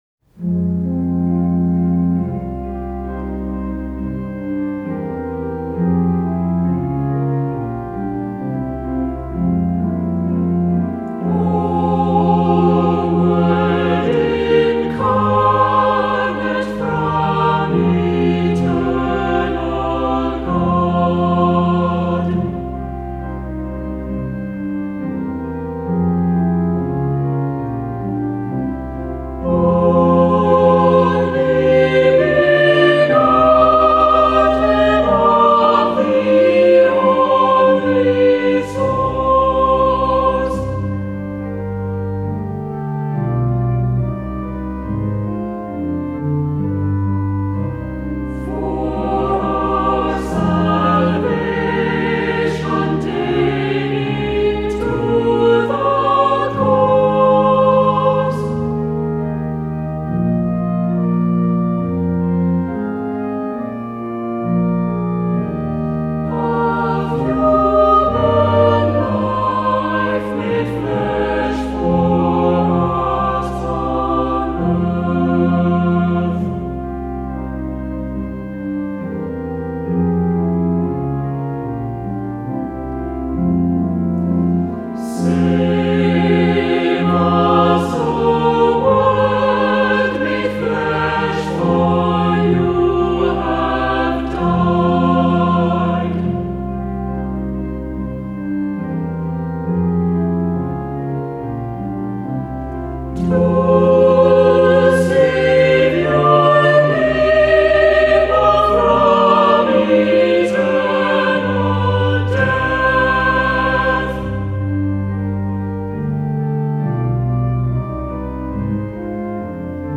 Voicing: "SAB"